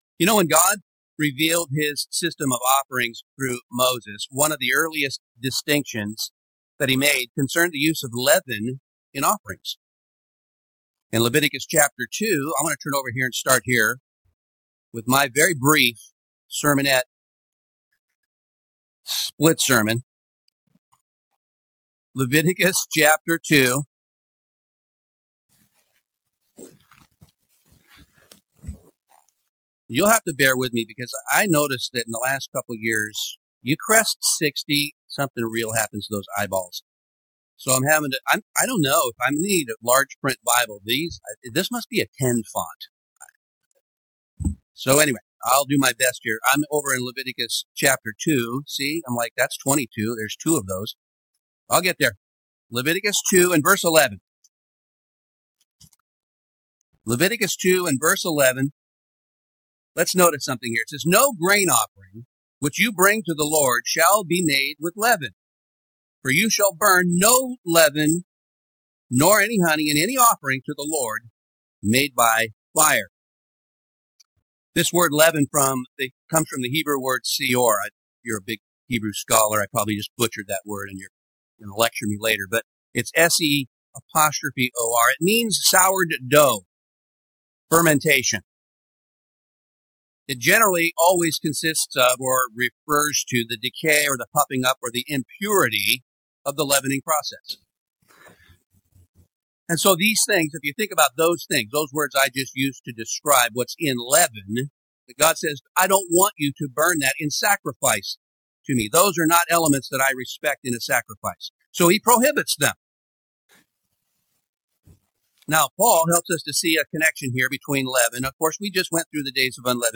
Pentecost 2025 Seattle/Tacoma: Why did God command leavened bread to be offered on Pentecost—when leaven is usually a symbol of sin? This message explores the rich symbolism of the two wave loaves: representing flawed, still-transforming human beings called from both Israel and the nations to become one Spirit-led Church.